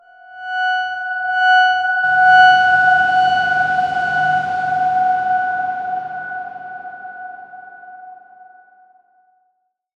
X_Darkswarm-F#5-mf.wav